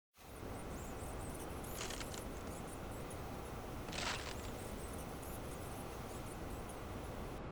楼道场景3.ogg